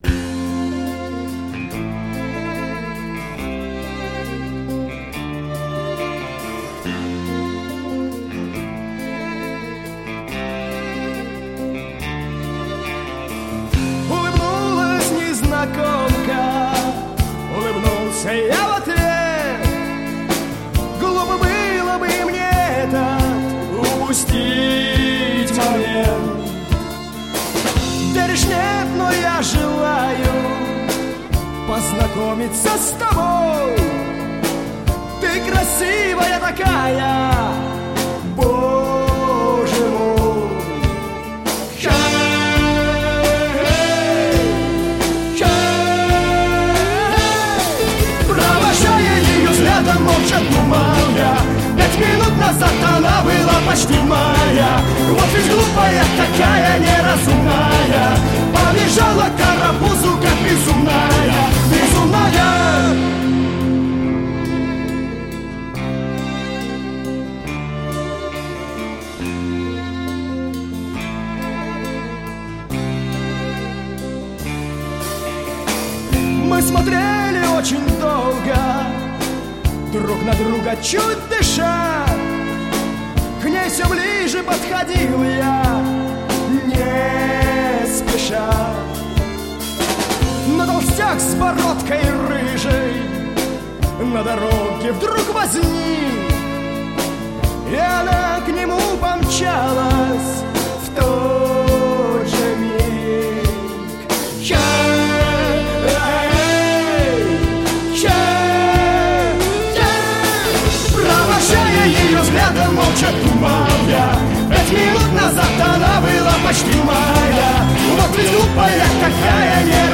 Жанр: Жанры / Рок